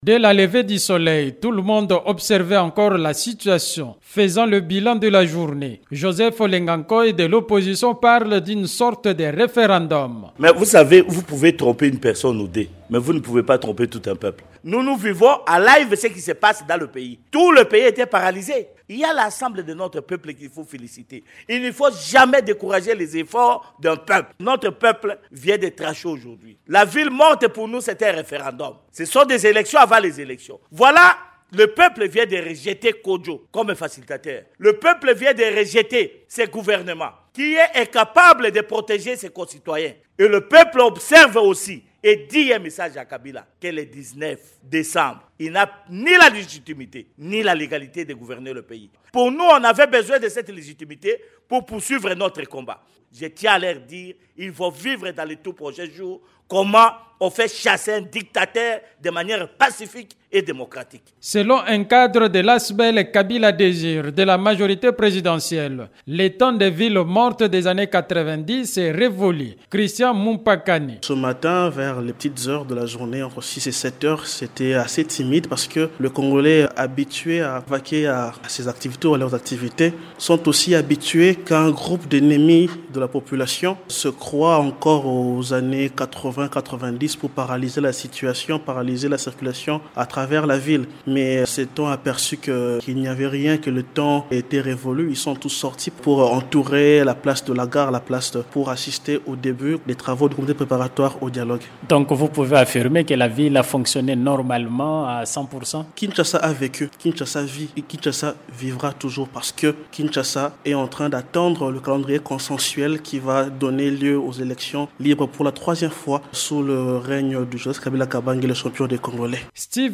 La journée ville morte peu suivie à Kinshasa-Reportage de Top Congo